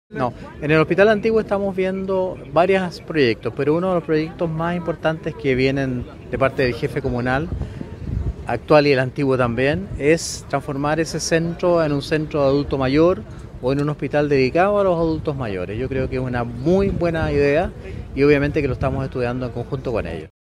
Consultado durante la inauguración del Hospital Biprovincial sobre la posibilidad de que el San Martín se reconvierta a un hospital de baja complejidad, para complementar su trabajo con el nuevo recinto, el titular de Salud fue enfático.
01-MINISTRO-PARIS-Una-muy-buena-idea.mp3